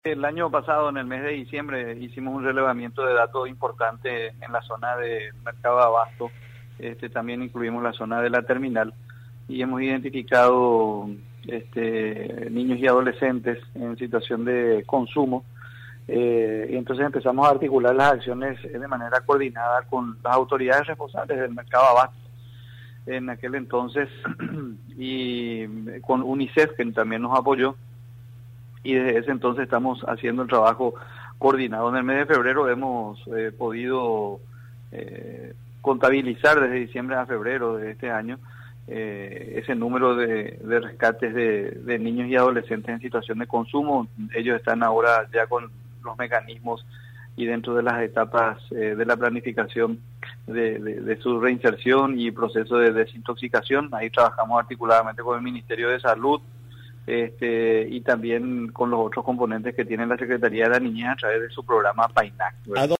Se cuenta con la colaboración del Ministerio de Salud Pública y Unicef, comentó el Ministro Ricardo González, en diálogo con Radio Nacional del Paraguay, en el Programa Tempranísimo.